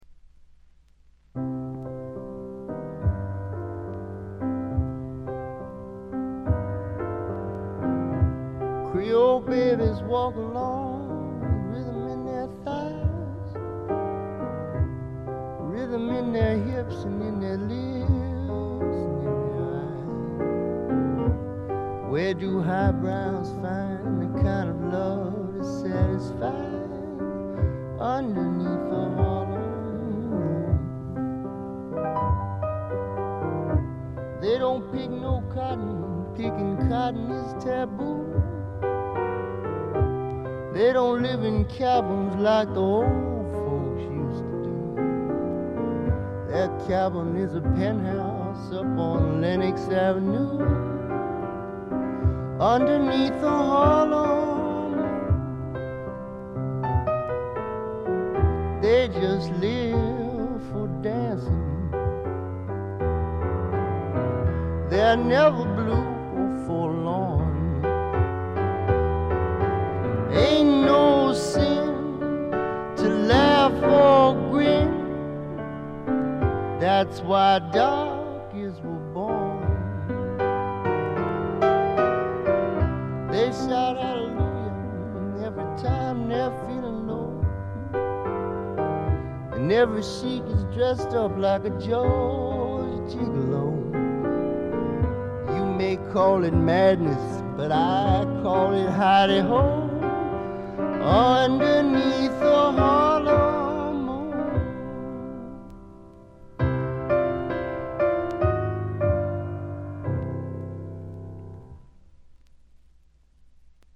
静音部で軽微なバックグラウンドノイズが少し。
試聴曲は現品からの取り込み音源です。
vocals, piano